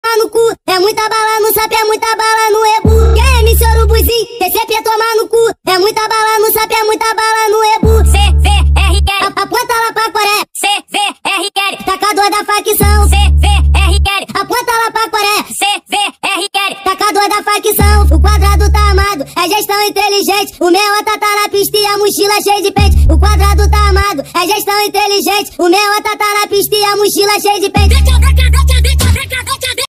2024-09-14 00:37:36 Gênero: Funk Views